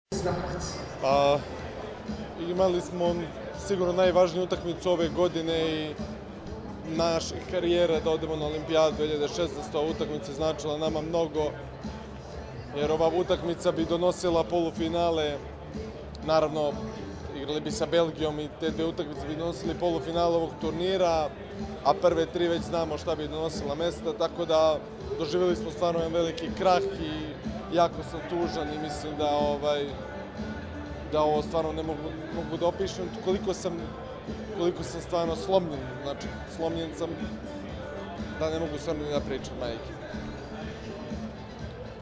IZJAVA UROŠA KOVAČEVIĆA